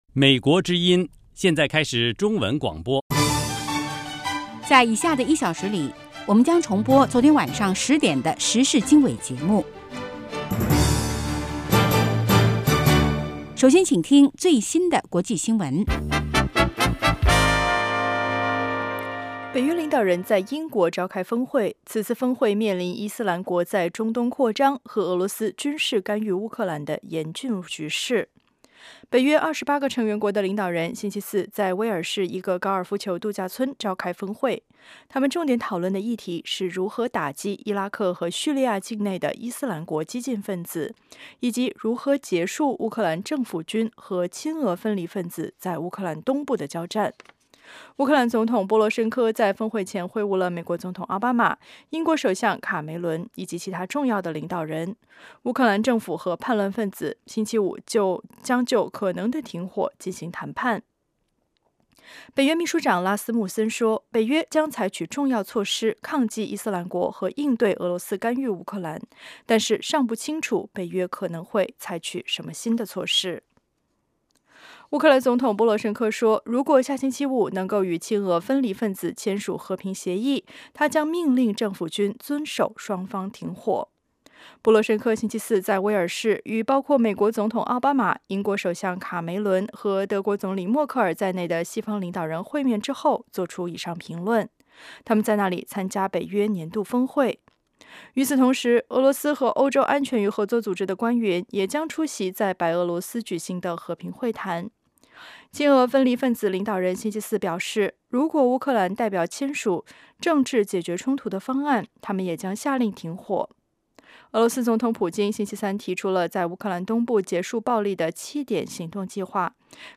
早6-7点广播节目